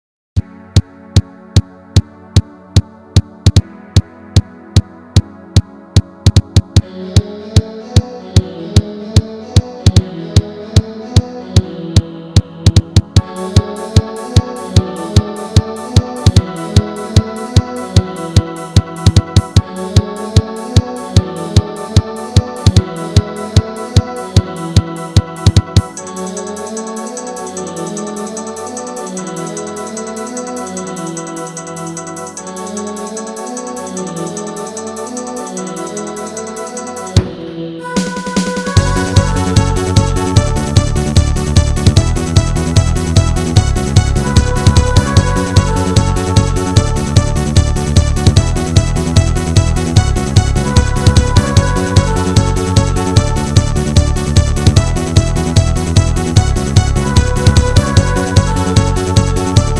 固件firm230328中，增加了新的“舞曲”功能，固件中给内置了30+首劲爆舞曲。
舞曲片段5